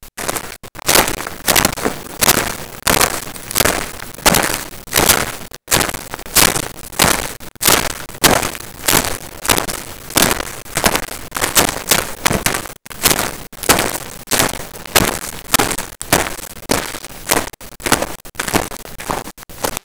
Footsteps Grass 02
Footsteps Grass 02.wav